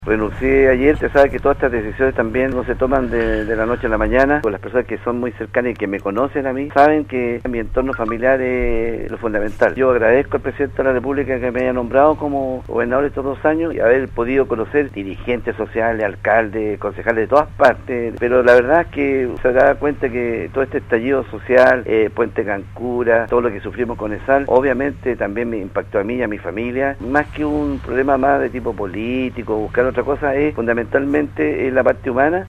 En conversación con Radio Sago, la ahora ex autoridad provincial de Osorno, Daniel Lilayú se refirió a los motivos de su reciente renuncia al cargo que ejerció por casi dos años.